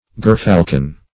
Gerfalcon \Ger"fal`con\, n. (Zool.)